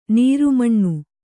♪ nīru maṇṇu